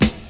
• 00s Hip-Hop Snare One Shot F# Key 07.wav
Royality free snare sample tuned to the F# note. Loudest frequency: 3271Hz
00s-hip-hop-snare-one-shot-f-sharp-key-07-yMv.wav